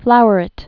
(flouər-ĭt, -ĕt)